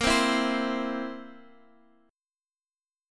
A#sus2b5 chord